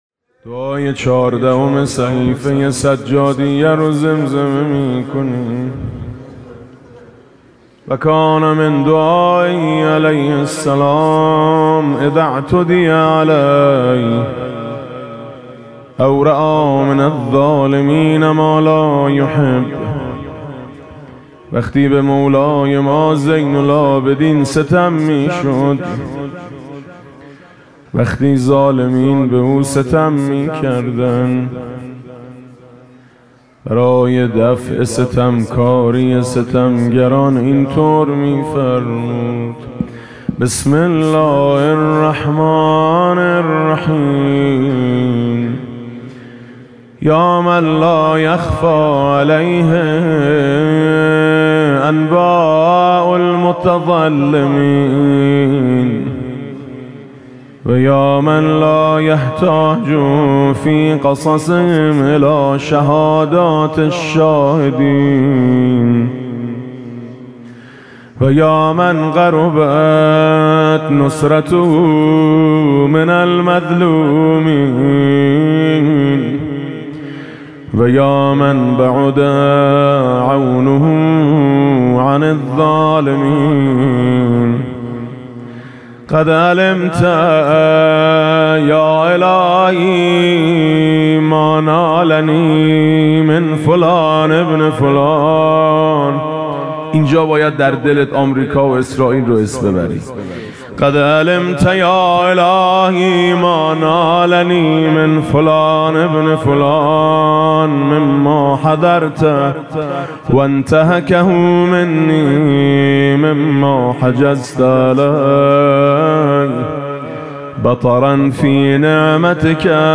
مناسبت: قرائت دعای ۱۴ صحیفه سجادیه و عزاداری ایام شهادت حضرت زهرا (س)
با نوای: حاج میثم مطیعی